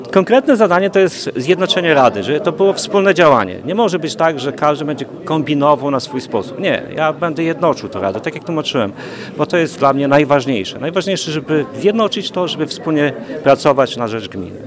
– Moim zadaniem jest jednoczenie rady dla dobra gminy – mówił zaraz po wyborze przewodniczący Mirosław Radywoniuk.
przewodniczacy-1.mp3